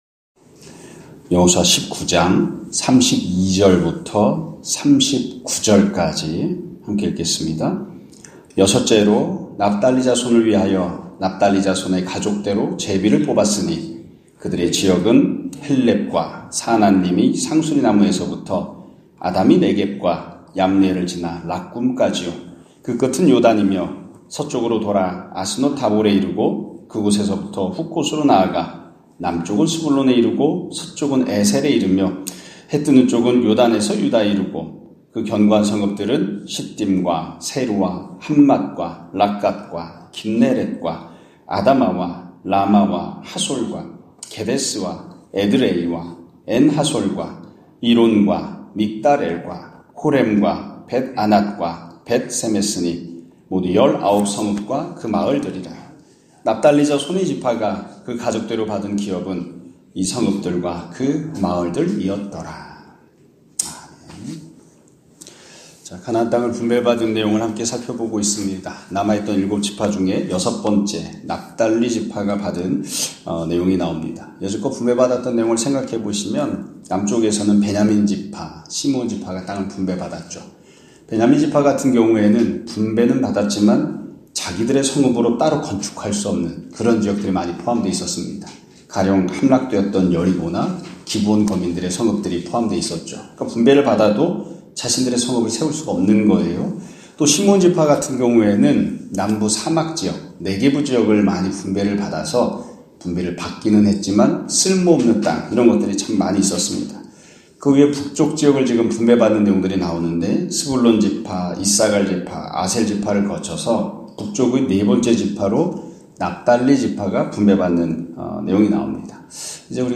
2025년 1월 9일(목 요일) <아침예배> 설교입니다.